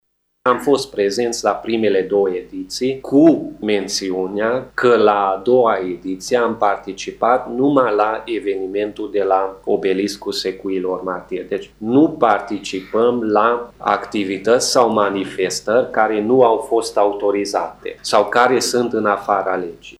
într-o conferinţă de presă